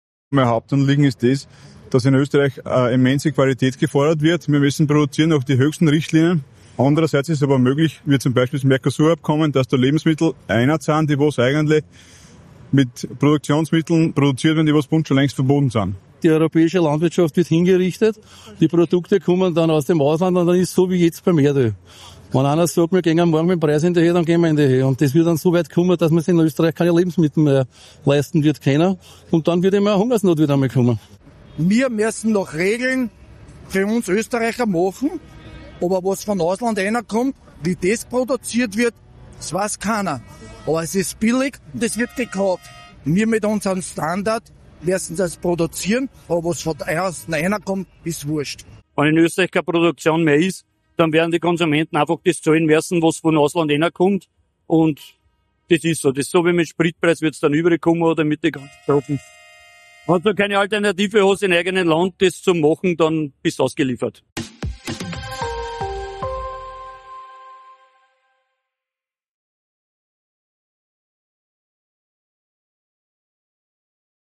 Wien wurde am Mittwoch Schauplatz eines großen Bauernprotests.